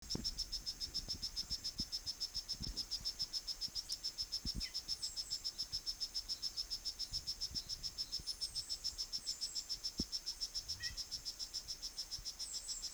Especie: Craugastor talamancae
Órden: Anura
Localidad: Panama: Bocas del Toro: Isla Popa
7560Craugastor talamancae.mp3